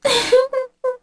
Kara-Vox_Sad1_kr.wav